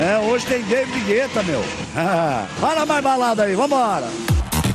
PLAY Galvão narrando LOLzinho
Play, download and share Galvão original sound button!!!!